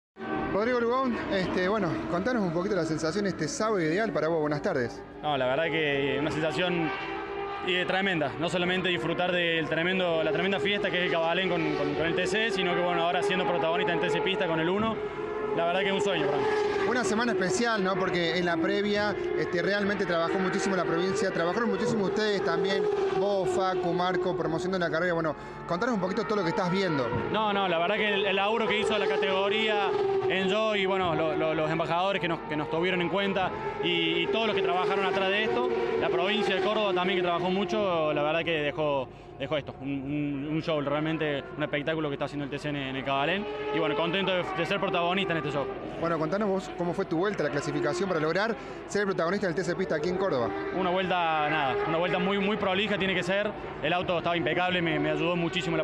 en diálogo exclusivo con CÓRDOBA COMPETICIÓN